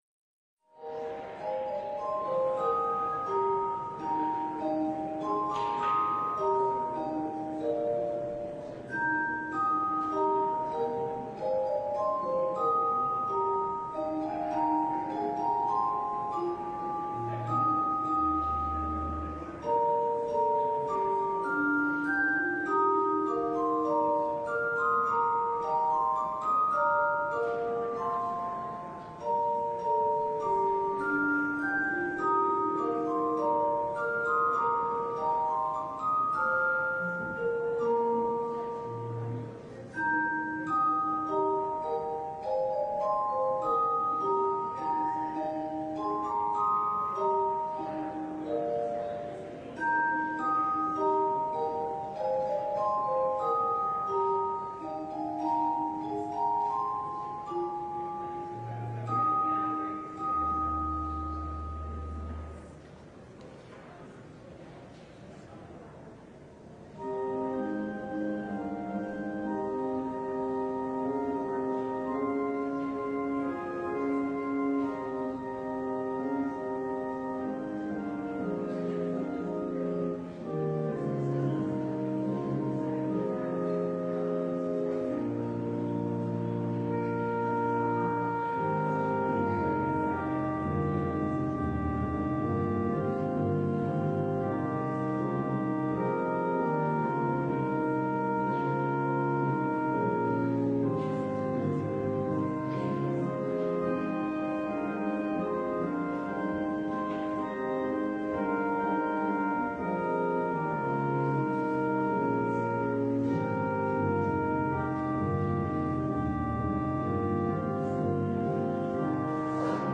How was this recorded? LIVE Morning Worship Service - The Prophets and the Kings: Elisha & the Arameans